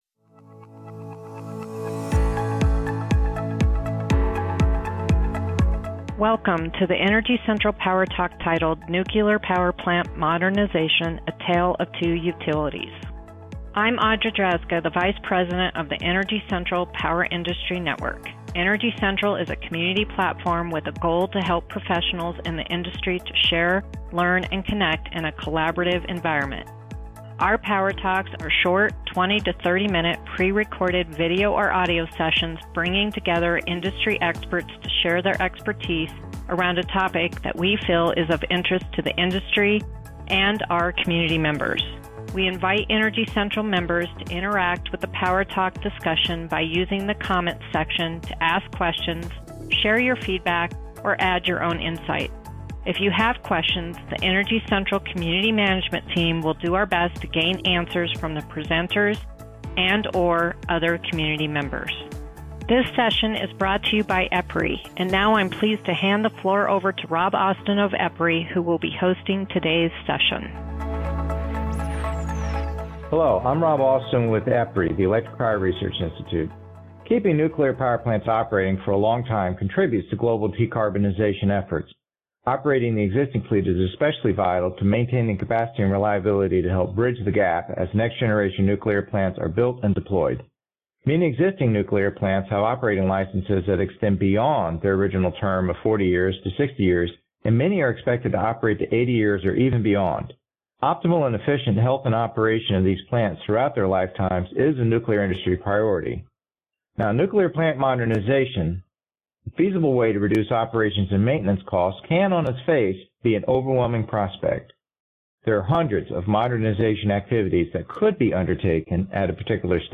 Energy Central PowerTalks - are short 20–30-minute pre-recorded video or audio recording sessions focused on a topic that we feel is of interest to the industry and our community members.